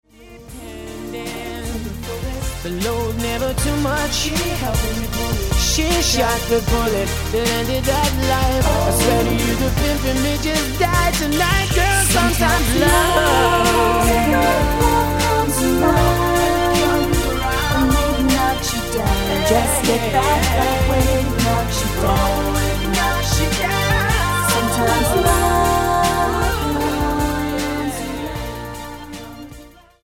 NOTE: Vocal Tracks 10 Thru 18